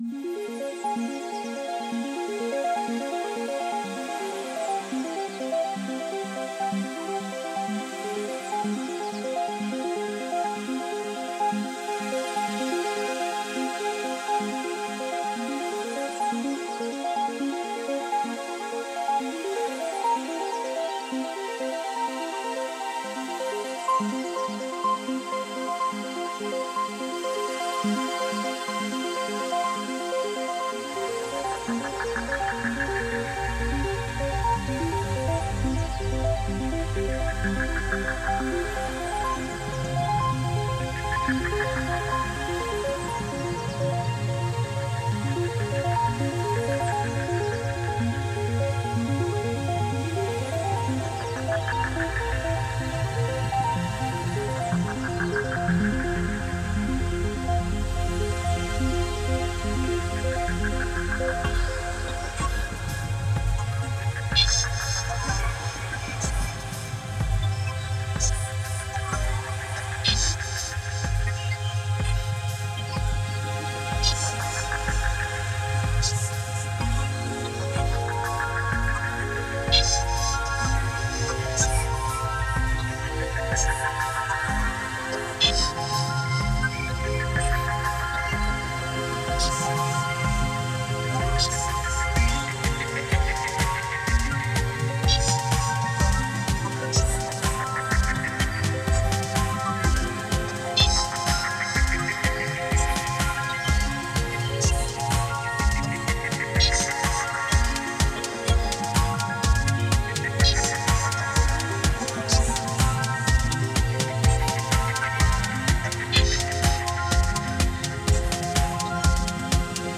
Genre: IDM, New Age.